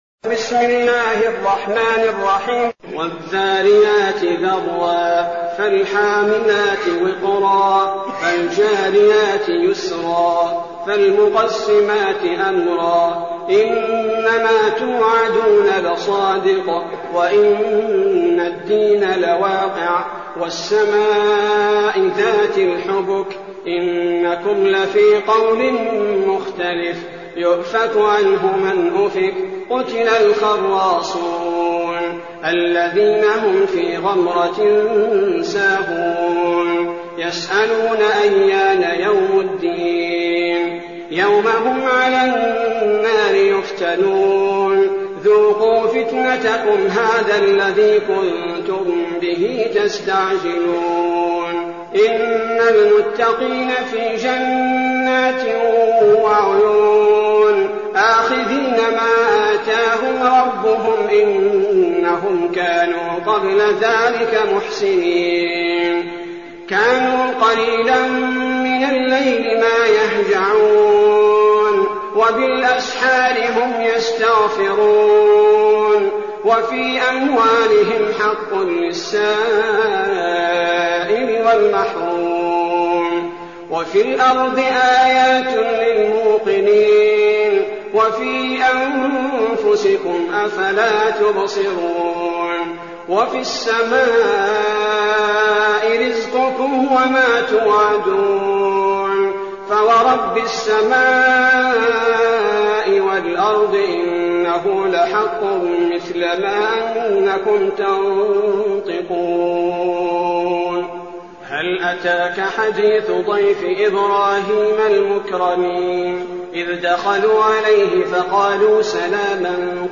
المكان: المسجد النبوي الشيخ: فضيلة الشيخ عبدالباري الثبيتي فضيلة الشيخ عبدالباري الثبيتي الذاريات